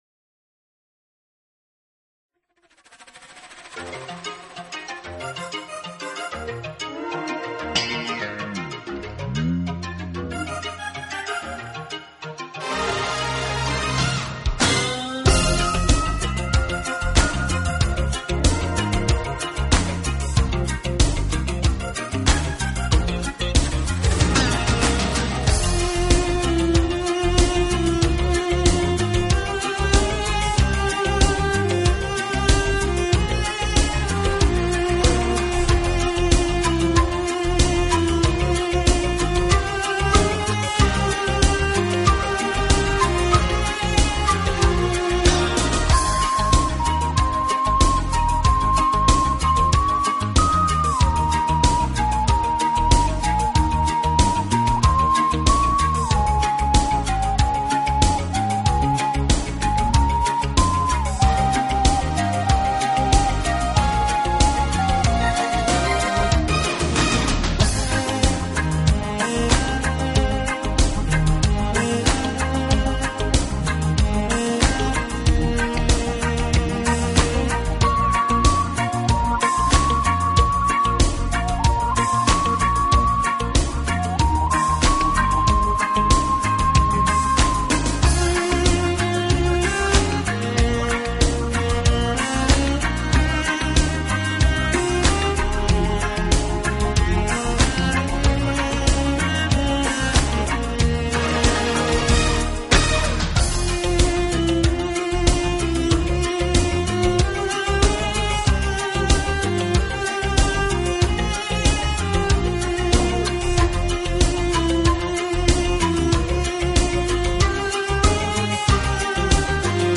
Acoustic（原音）是指原声乐器弹出的自然琴声（原音），制作录音绝对不含味精，乐
陶笛就像乐器中的一个小精灵，声音不大，却有着天籁般晶莹剔透的音色。